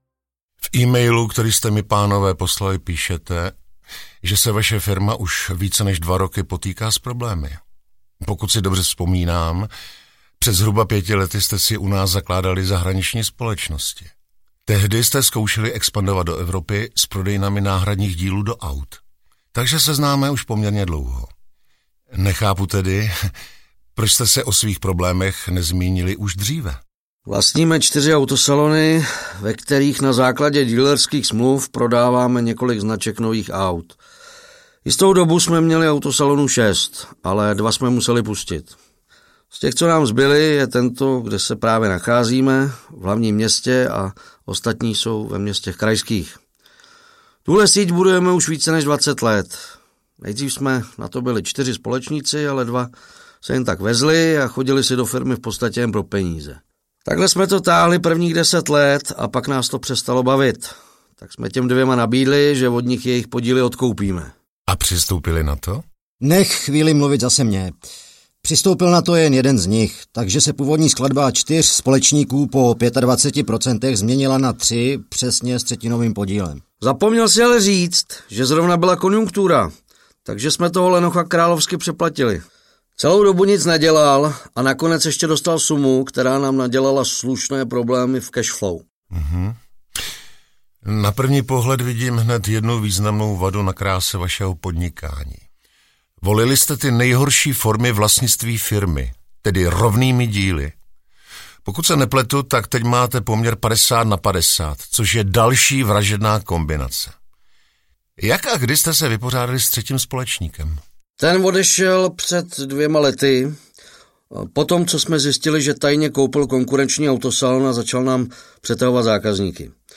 Krotitel rizik podnikání zasahuje: Autosalon audiokniha
Ukázka z knihy
Spojení zajímavých příběhů s hlasy známých českých herců podtrhuje atraktivitu celého projektu. V prvním díle zasahuje krotitel v autosalonu, ve kterém se dva spolumajitelé nemohou už téměř na ničem shodnout a jediným řešením je jejich podnikatelský rozvod.
• InterpretAlexej Pyško, David Punčochář, Tomáš Jeřábek